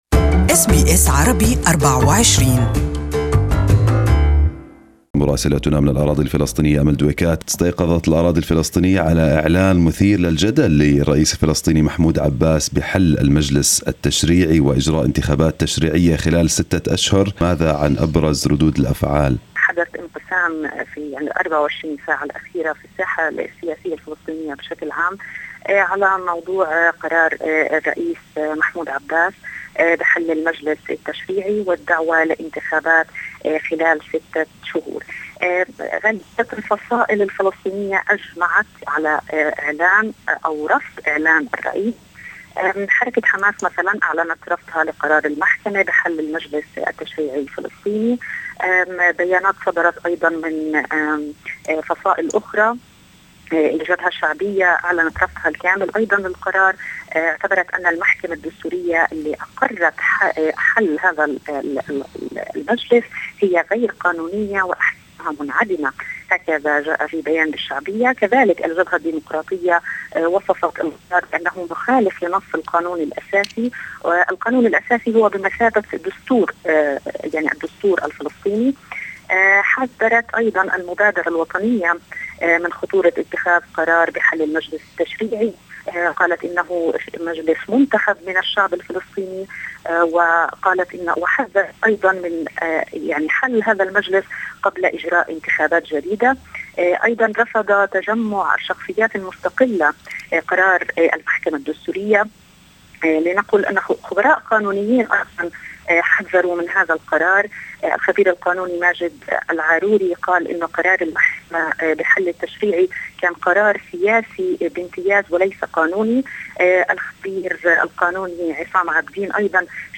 Listen to the full report in Arabic.